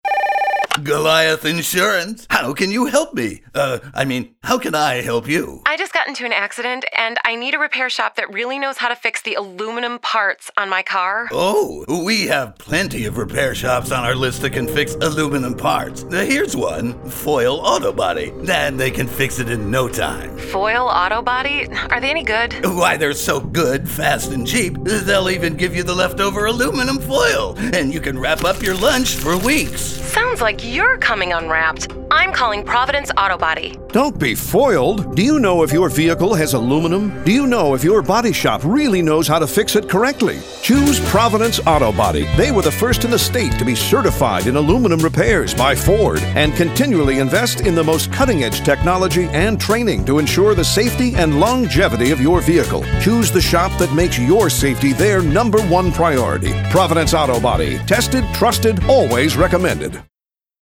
Radio Commercials: